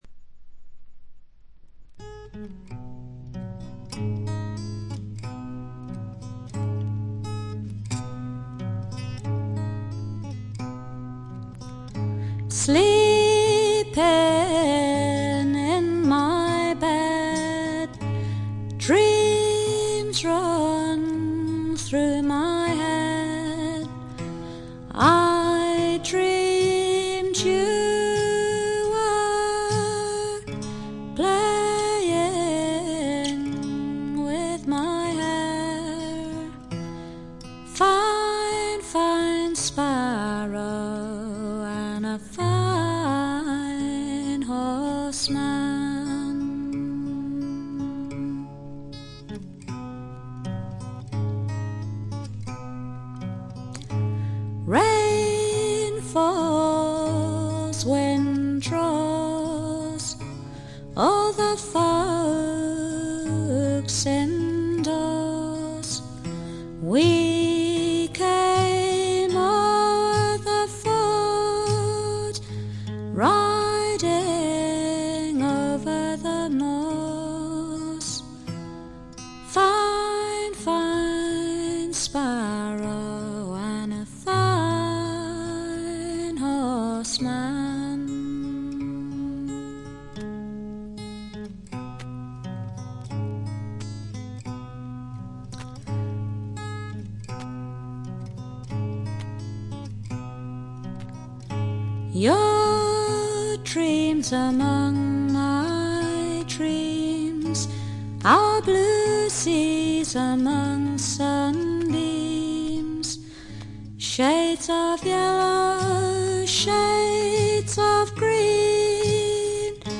演奏面は彼女自身が奏でるギター、ブズーキだけと非常にシンプルなもの。
アルバム全体はしっとりした雰囲気で、不思議な浮遊感があり少しくぐもったかわいい歌声が「夢の世界」を彷徨させてくれます。
試聴曲は現品からの取り込み音源です。